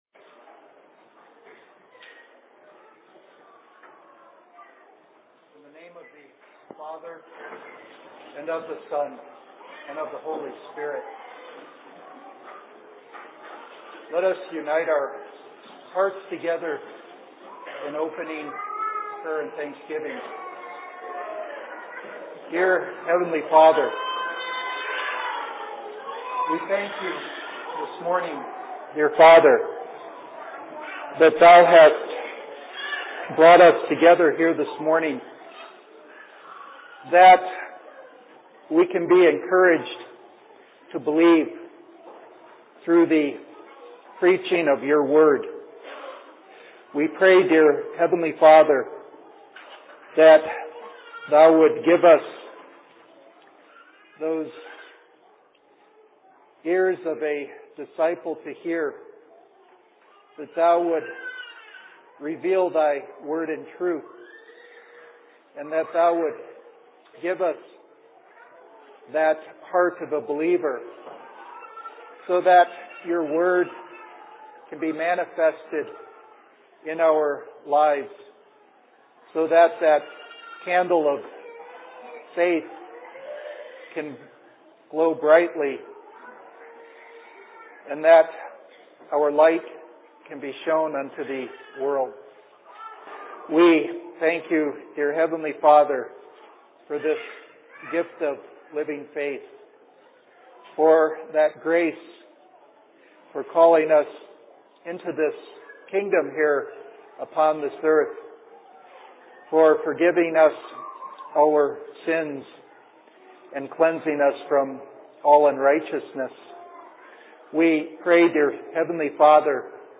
Sermon in Phoenix 11.03.2007
Location: LLC Phoenix